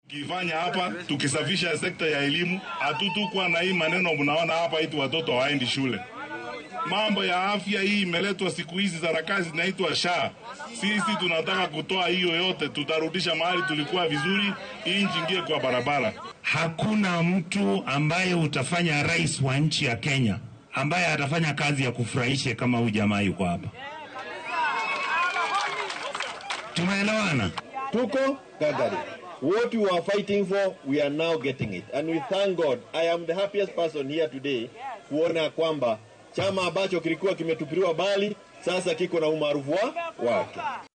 Ku xigeenka hoggaamiyaha xisbiga Jubilee Dr. Fred Matiang’i ayaa isku soo bandhigay musharraxa ugu habboon ee qaban kara xilka madaxtinimada dalka ee doorashada sannadka soo socda. Isagoo ka hadlayay furitaanka xaruunta gobolka iyo xaruunta xisbiga ee ismaamulka Nakuru, gaar ahaan Section 58, ayuu sheegay in haddii loo doorto madaxweynaha lixaad ee waddanka uu mudnaanta siin doono dib u habeyn lagu sameeyo waaxyaha caafimaadka iyo waxbarashada.